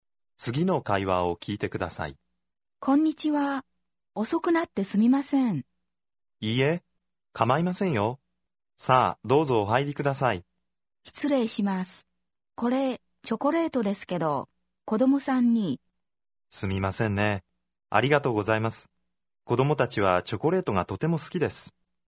Аудио курс для самостоятельного изучения японского языка.